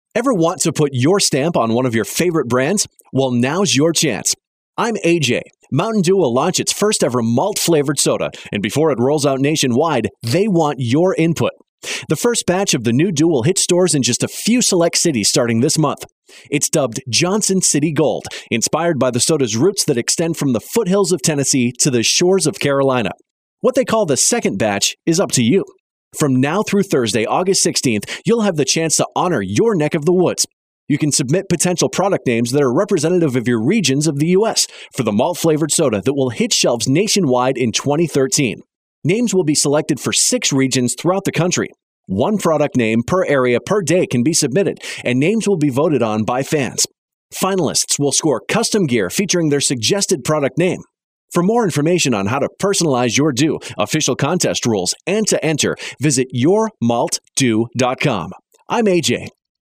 August 13, 2012Posted in: Audio News Release